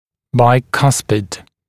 [baɪ’kʌspɪd][бай’каспид]премоляр, малый коренной зуб (человека)